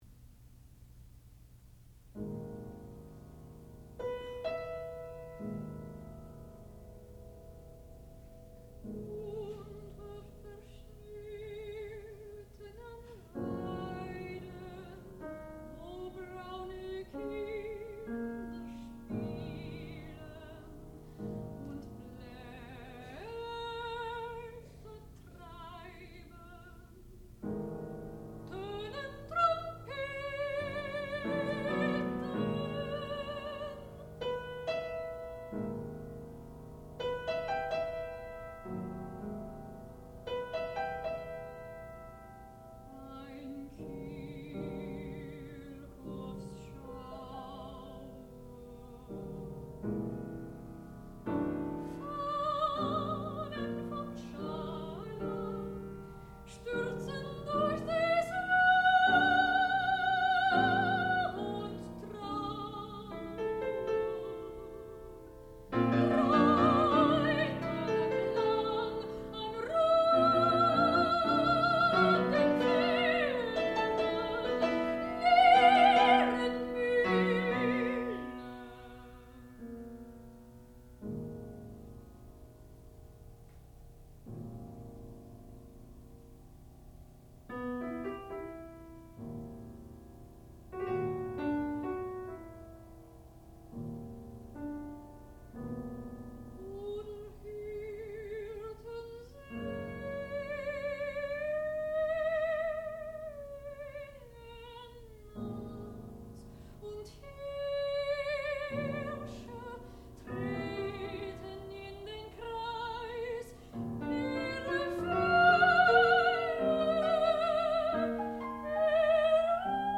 sound recording-musical
classical music
piano
soprano